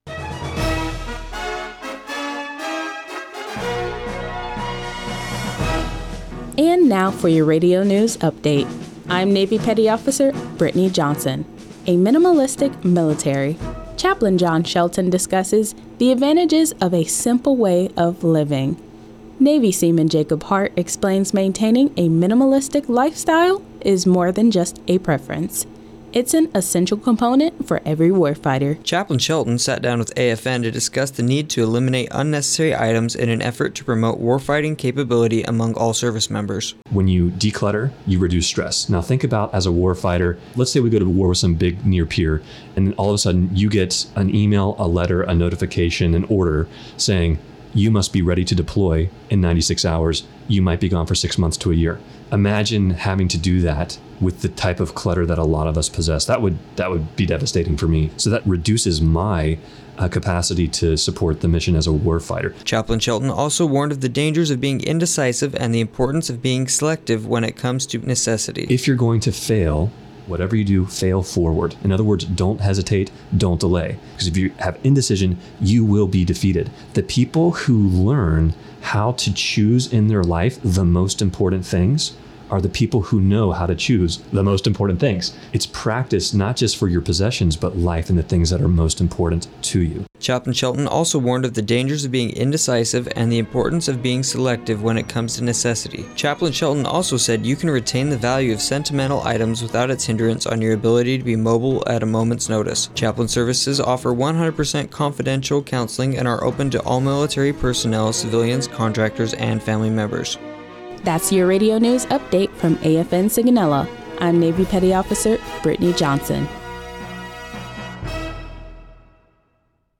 NAVAL AIR STATION SIGONELLA, Italy (Oct. 4, 2024) Radio spot promotes the importance of maintaining a minimalistic lifestyle in the military.